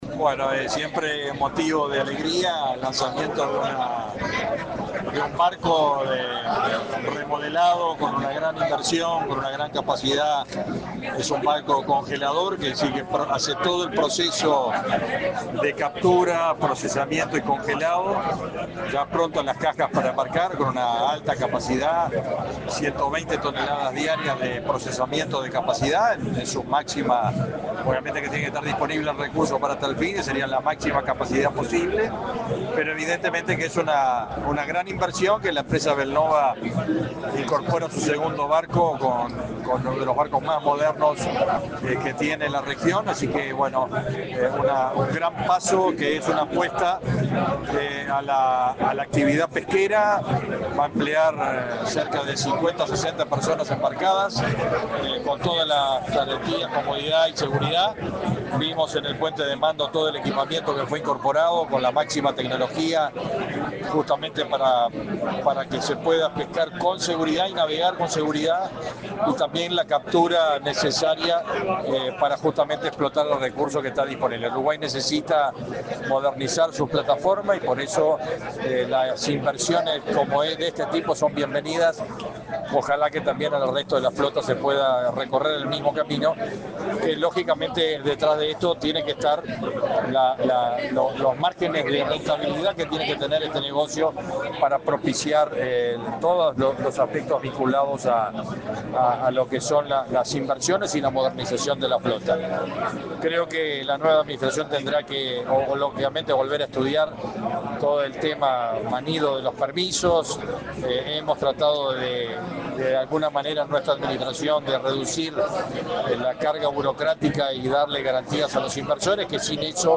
Declaraciones del ministro de Ganadería, Fernando Mattos
El ministro de Ganadería, Fernando Mattos, dialogó con la prensa en el Puerto de Montevideo, donde participó de la ceremonia de bienvenida al buque